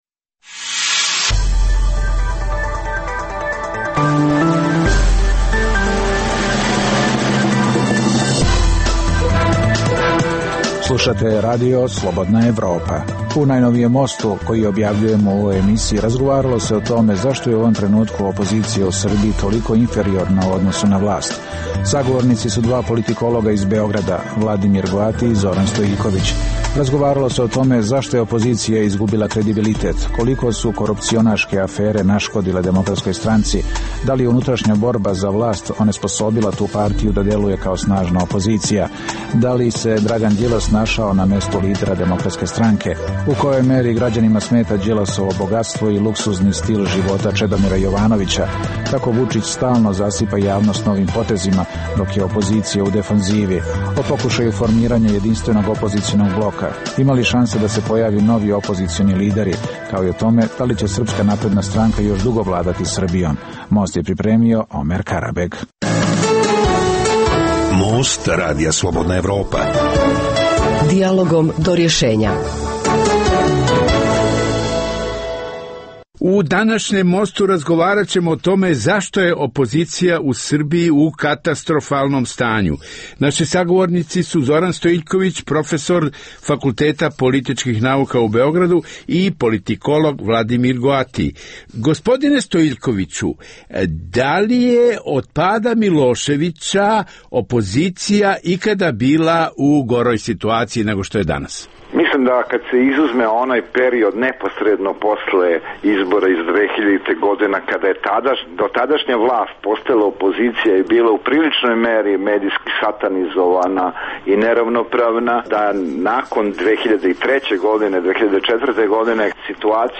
Emisija o dešavanjima u regionu (BiH, Srbija, Kosovo, Crna Gora, Hrvatska) i svijetu.